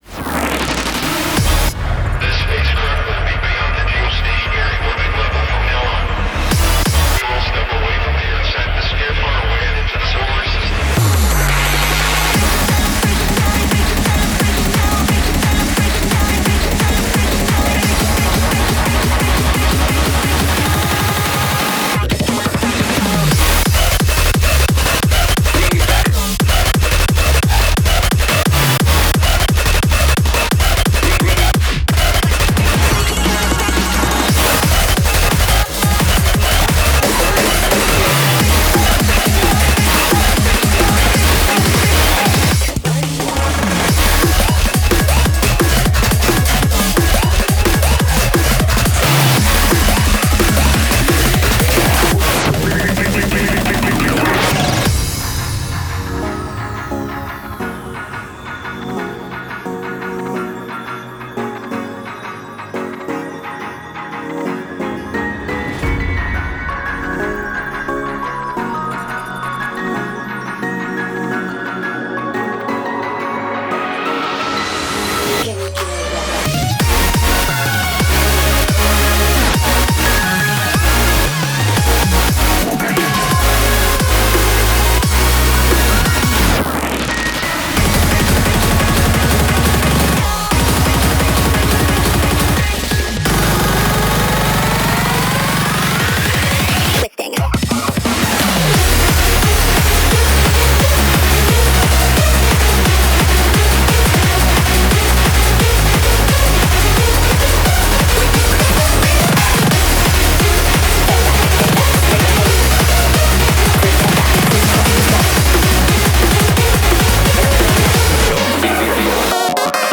BPM88-175